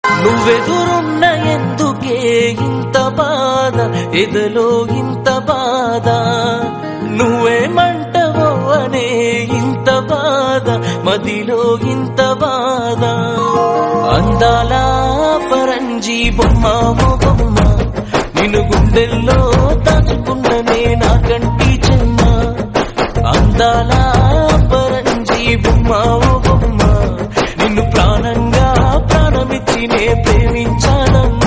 Telugu Ringtonelove ringtonemelody ringtoneromantic ringtone